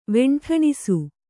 ♪ veṇṭhaṇisu